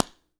Clap21.wav